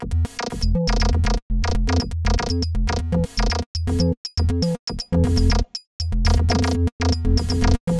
And a few crazy loops, mostly done with random kits and functions.